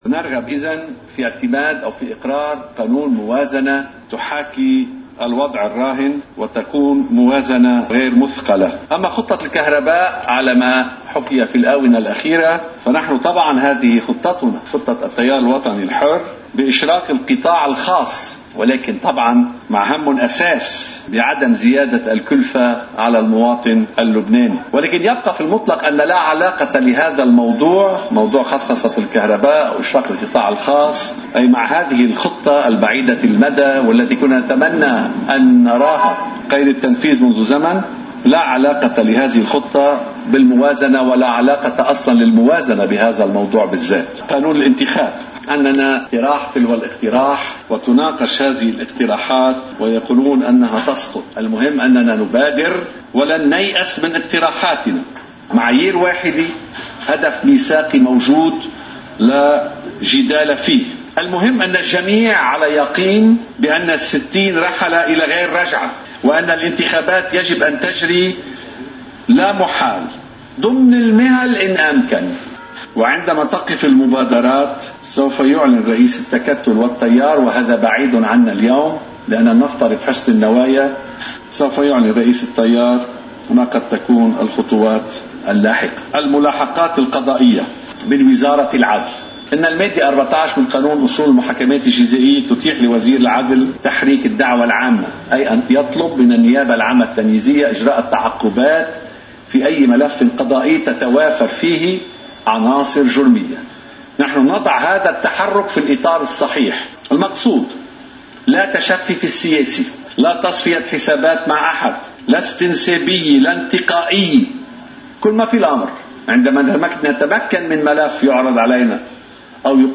مقتطف من حديث وزير العدل سليم جريصاتي بعد أجتماع تكتّل التغيير والإصلاح في الرابية: